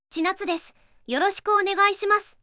VOICEVOXでテキストからwavファイルを作成します。